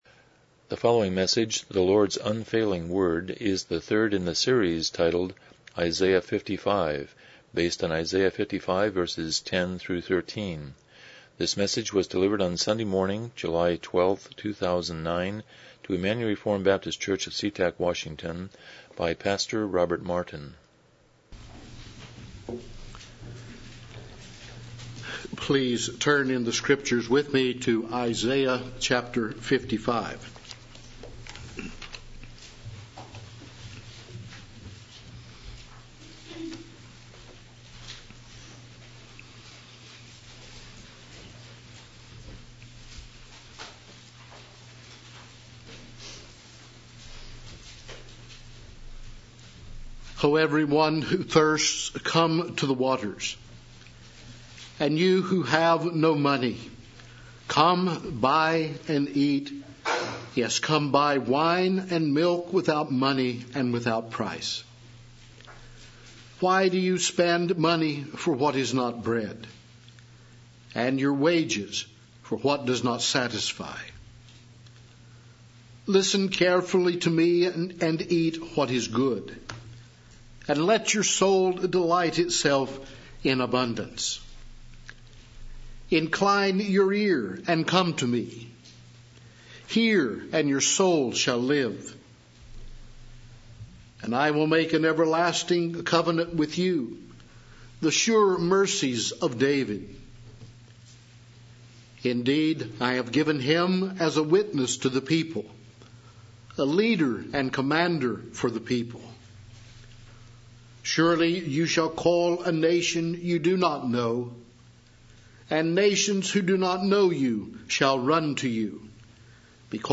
Isaiah 55:10-13 Service Type: Morning Worship « 38 What is a Covenant?